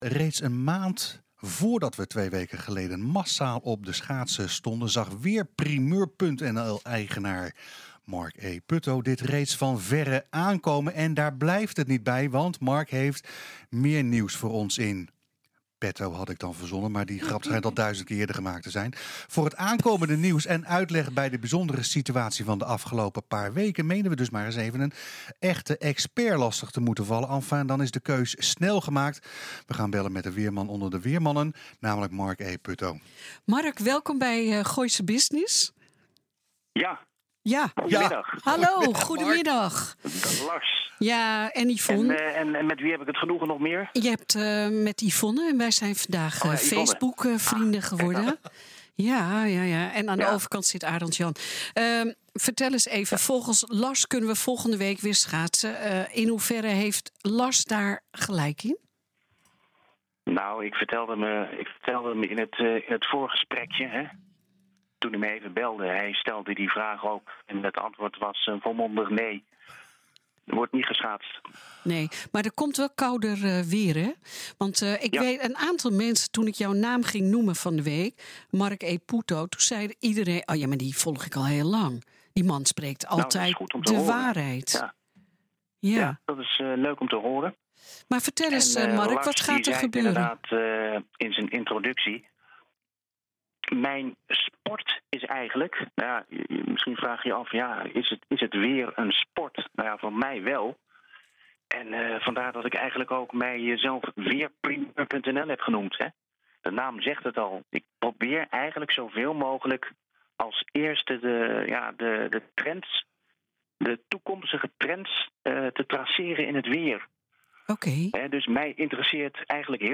Enfin dan is de keus snel gemaakt, we gaan bellen met de weerman onder de weermannen …